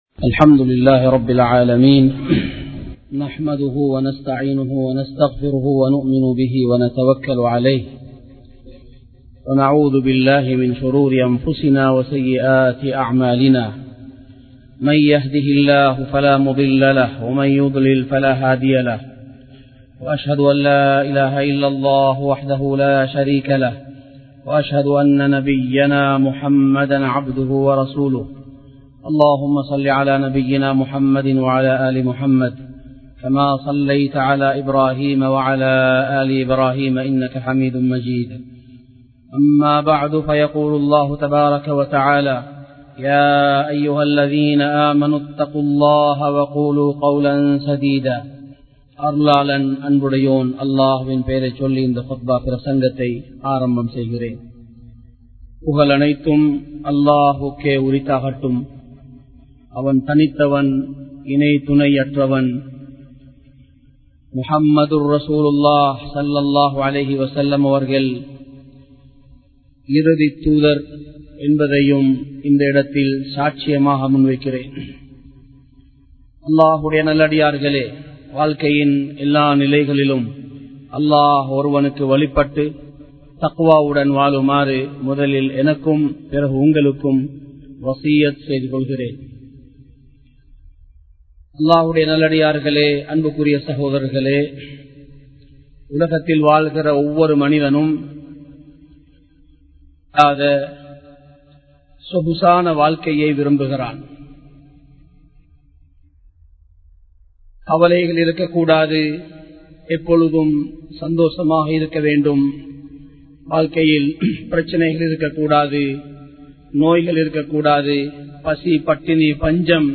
அல்லாஹ்வை மறந்து விடாதீர்கள் | Audio Bayans | All Ceylon Muslim Youth Community | Addalaichenai
Colombo 03, Kollupitty Jumua Masjith